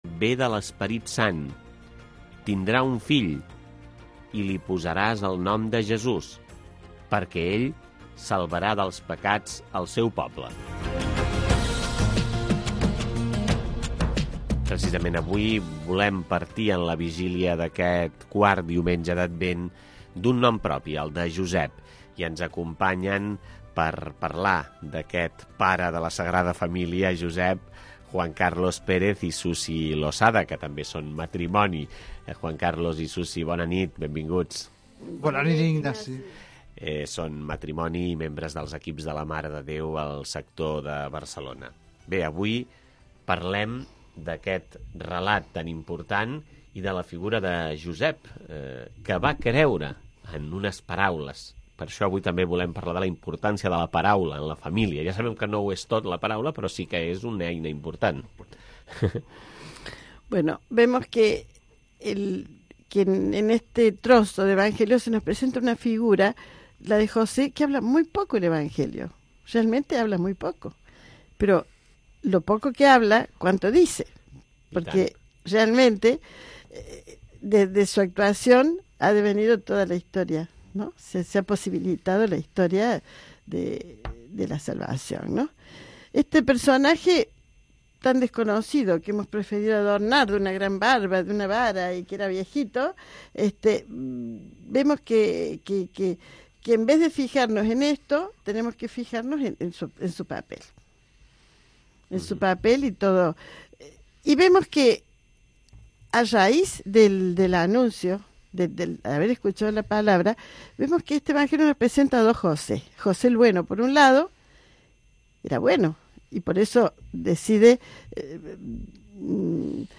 Religió
FM